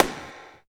134 SNARE.wav